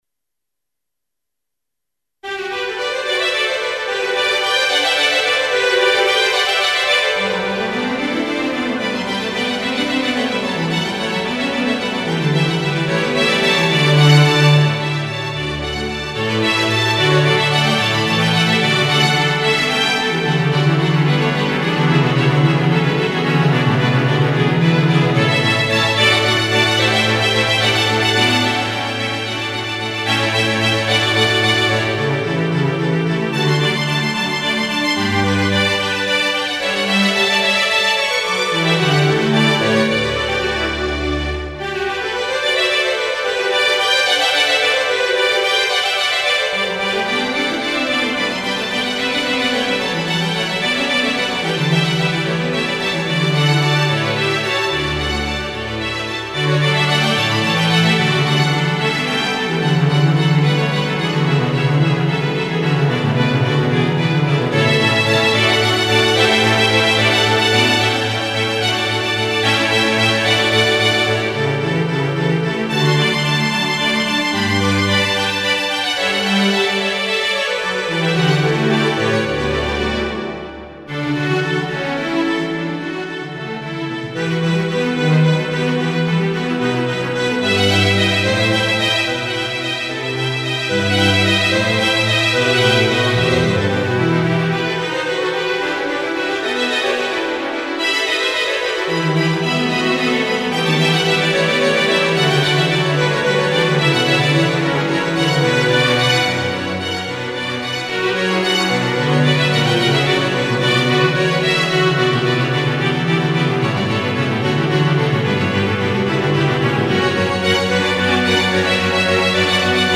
Made with "Miroslav Philharmonik"